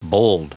Prononciation du mot bowled en anglais (fichier audio)
Prononciation du mot : bowled